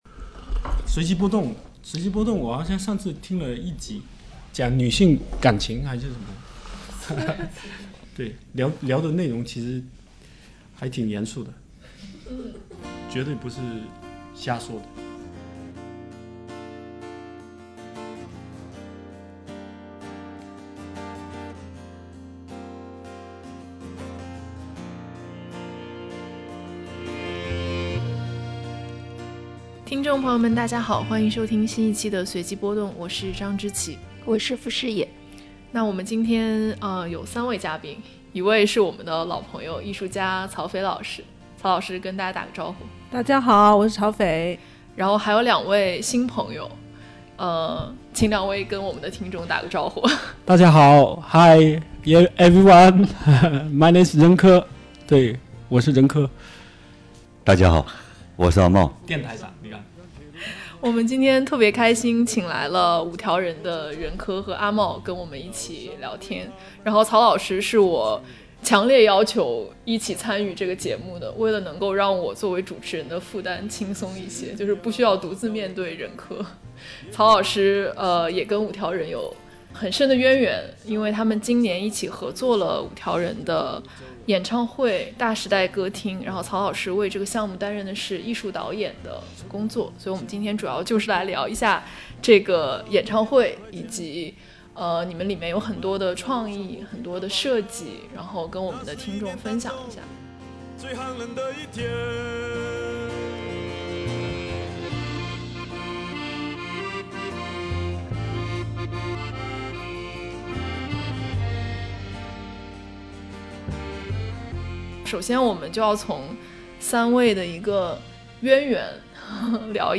✨【随机波动153】和五条人聊天：飘在空中的思想啊，我捕捉不到